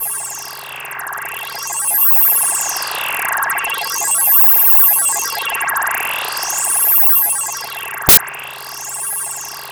SYS-100M LFO.wav